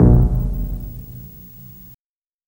Drum and Bass/Instrument Samples
Si Fi.22.wav